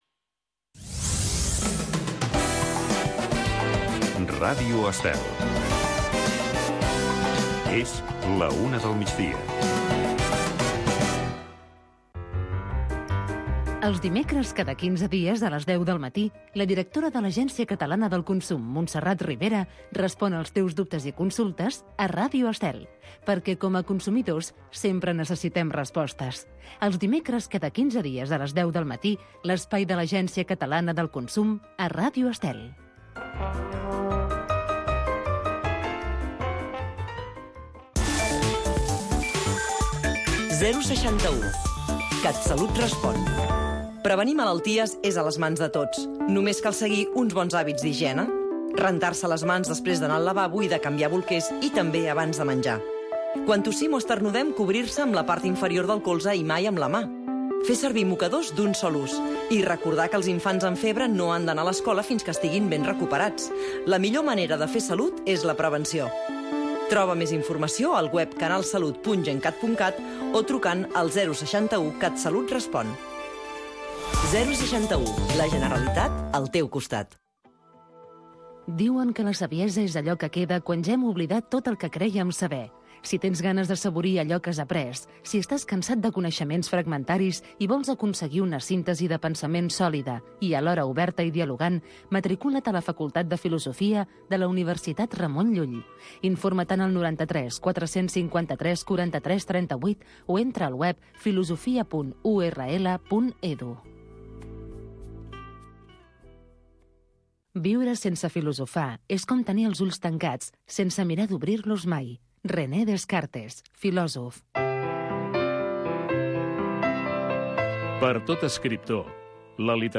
Tradicions, festes, cultura, rutes, combinat amb entrevistes i concurs per guanyar molts premis.